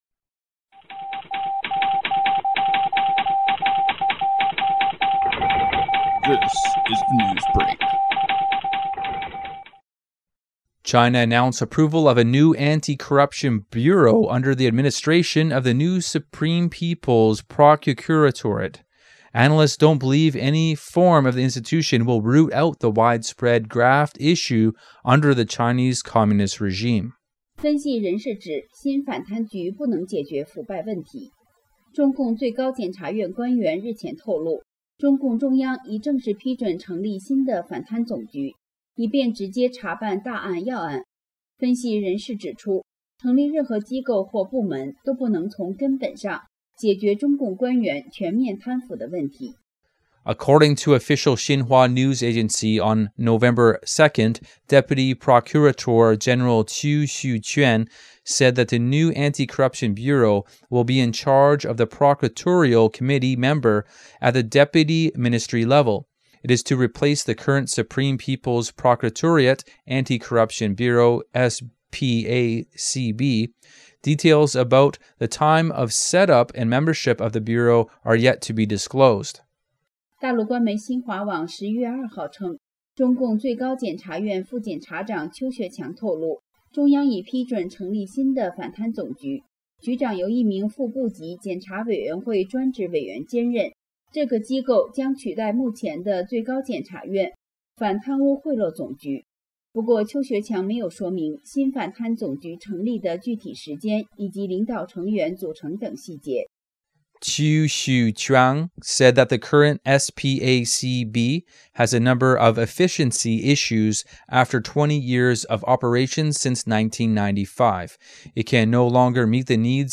Type: News Reports
128kbps Mono